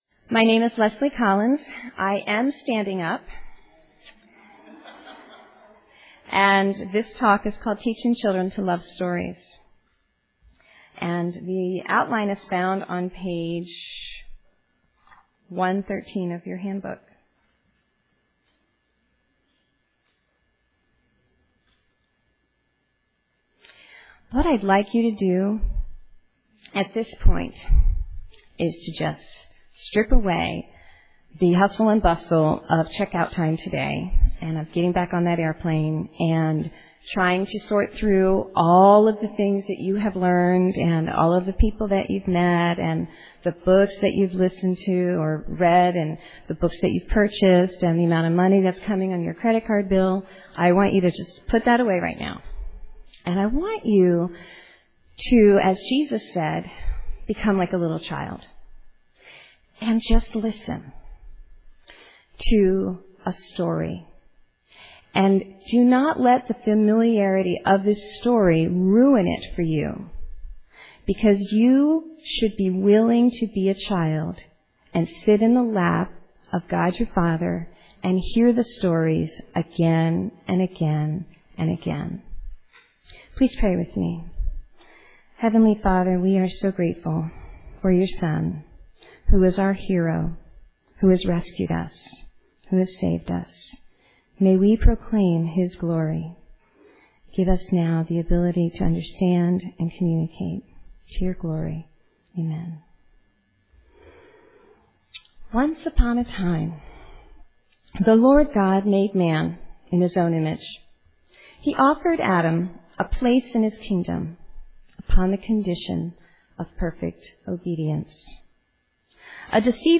2007 Workshop Talk | 0:58:26 | K-6, Literature